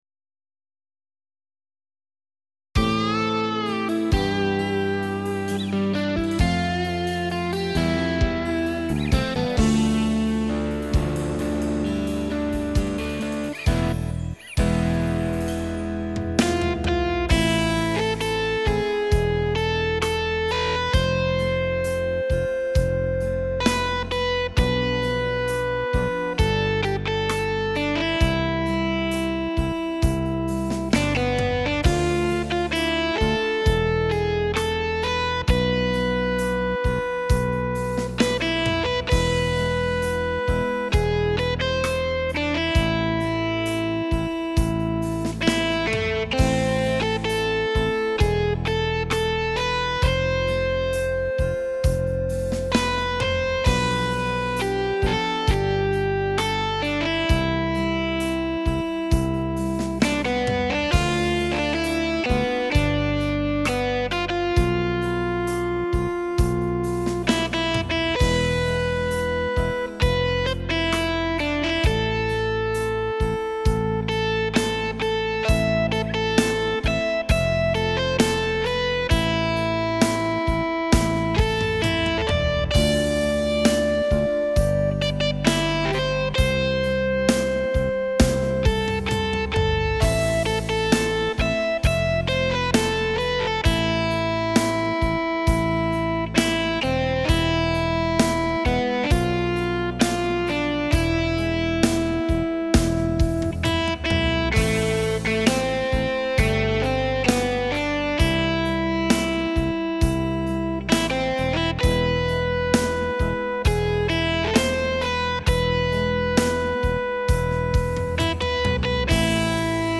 Keyboard
Rock version:
NiemRiengLenHoi_Rock-1.mp3